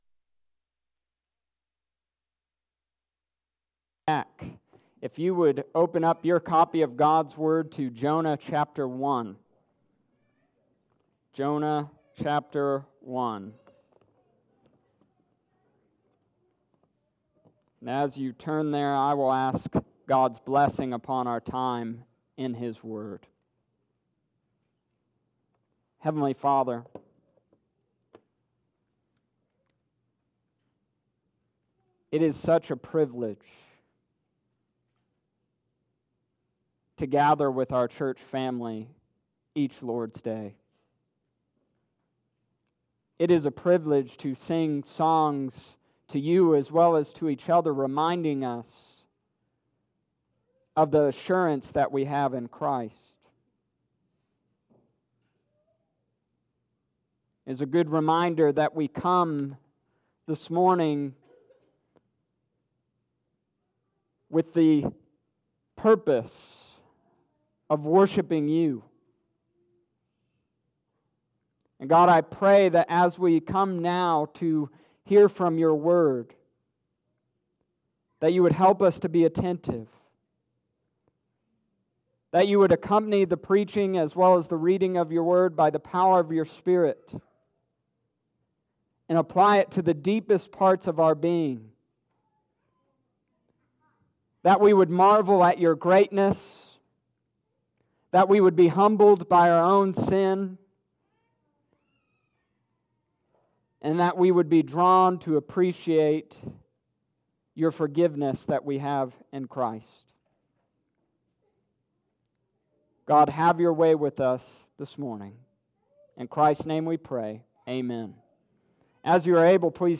Your Shocking God: Johna 1:1-16 Sept. 1st, 2019 Sunday Morning Service
Summary of Sermon: This week, Jonah reveals some shocking things about our God.